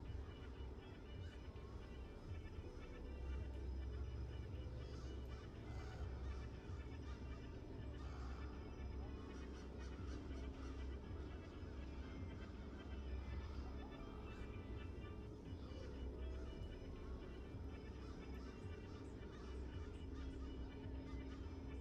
amb_lunapark_2.wav